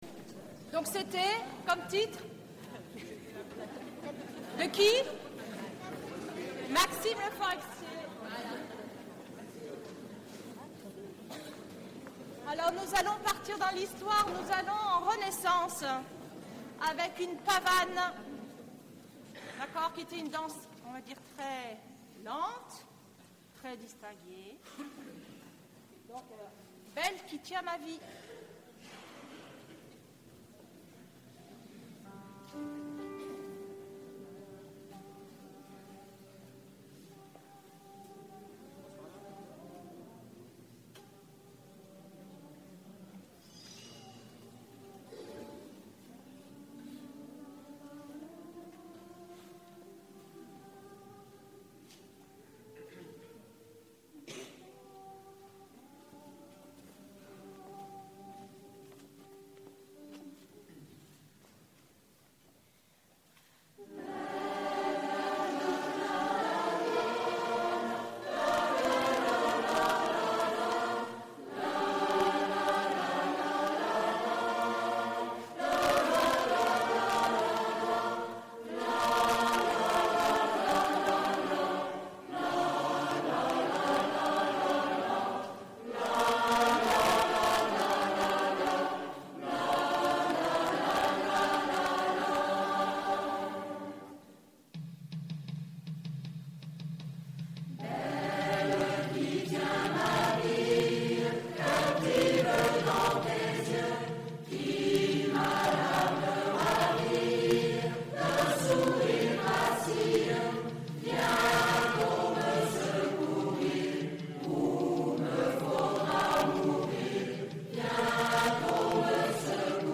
Concert à l’église : Après les images, la vidéo, voici le son..